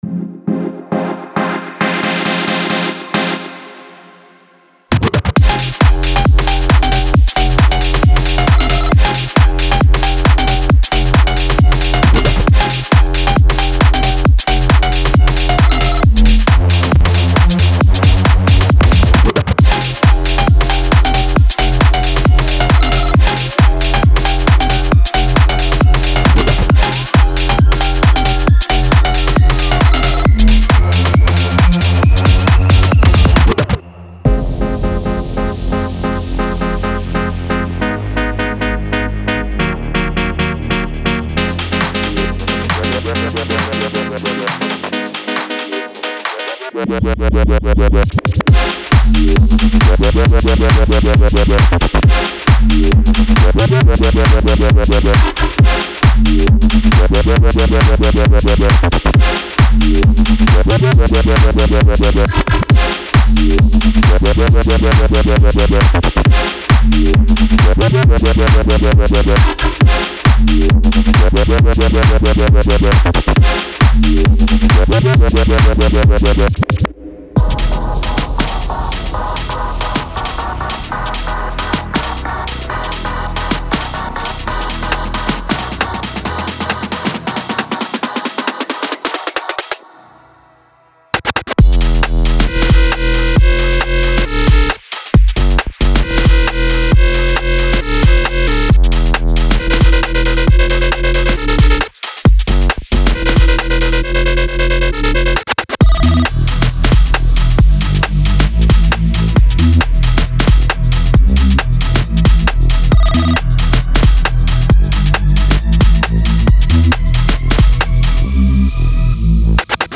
Genre:Garage
速すぎず、決して遅くもないその中間の心地よさです。
メロディ面では、アルペジオシンセ、リード、スタブ、プラックなども揃っています。
デモサウンドはコチラ↓